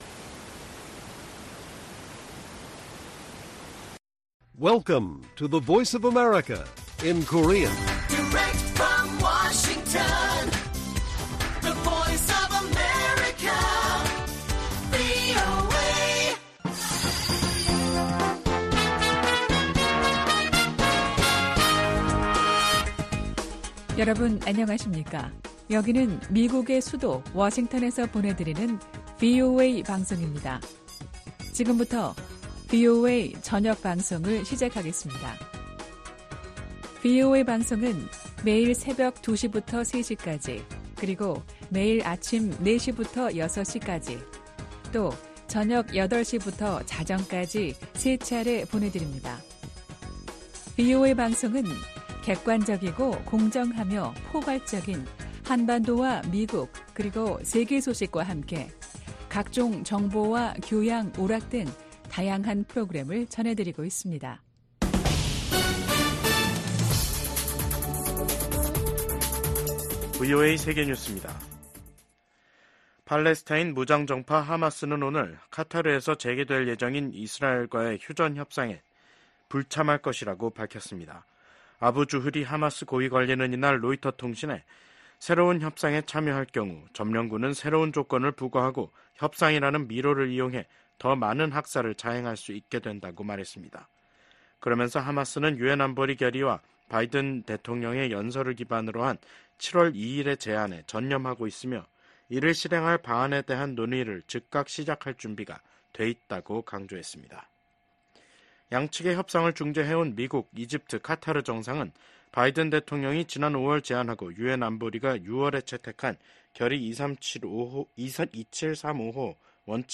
VOA 한국어 간판 뉴스 프로그램 '뉴스 투데이', 2024년 8월 15일 1부 방송입니다. 조 바이든 미국 대통령은 퇴임 의사를 밝힌 기시다 후미오 일본 총리가 미한일 협력 강화에 기여했다고 평가했습니다. 윤석열 한국 대통령은 광복절을 맞아 자유에 기반한 남북한 통일 구상과 전략을 ‘독트린’이라는 형식으로 발표했습니다. 북한이 2018년 넘긴 55개 유해 상자에서 지금까지 미군 93명의 신원을 확인했다고 미국 국방부 당국자가 말했습니다.